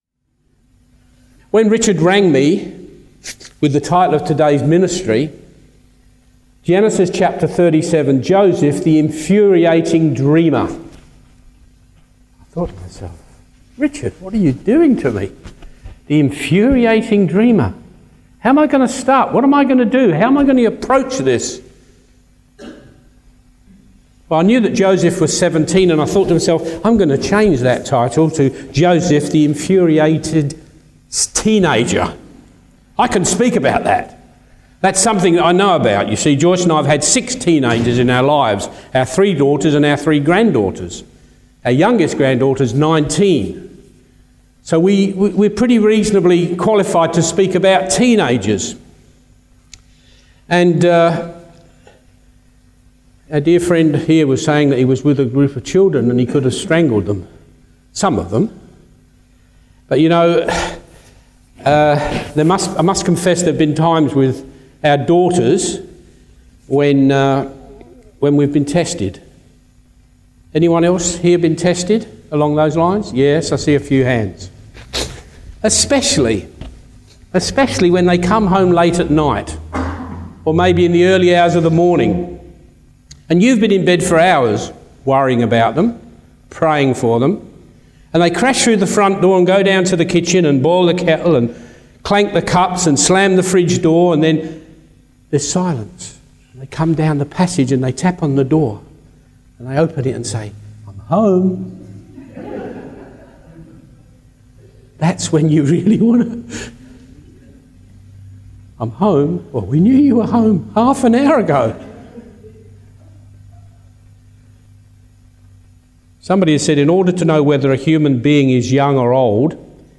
Genesi 37 Service Type: Family Service Topics: Joseph's Dreams « Rising with Jesus Joseph